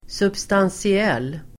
Uttal: [substansi'el:]